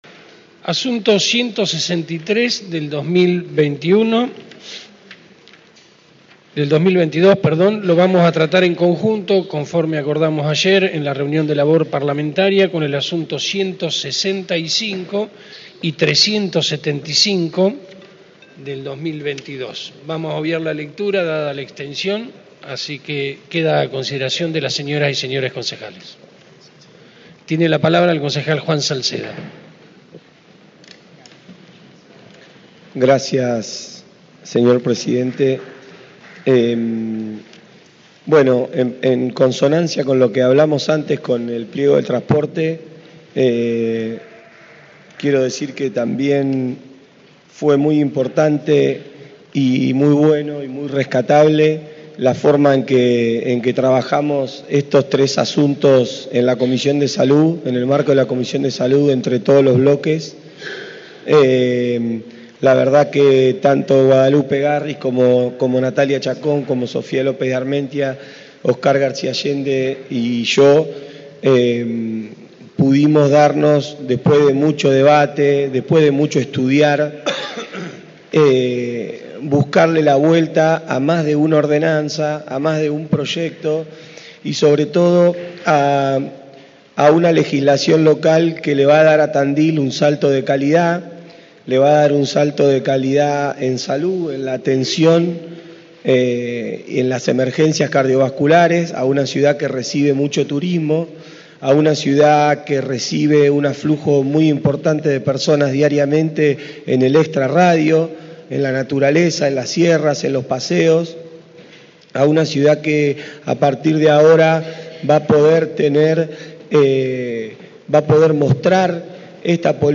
Audios de sesiones